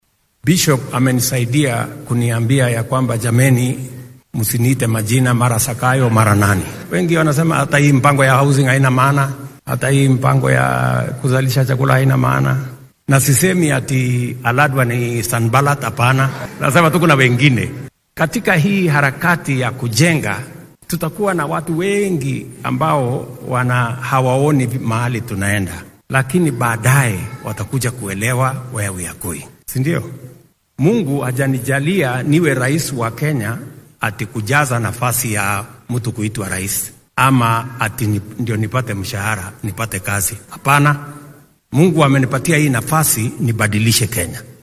Arritan ayuu ka sheegay kaniisad ku taalla xaafadda Bahati ee deegaanka Makadara ee ismaamulka Nairobi.